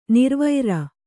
♪ nirvaira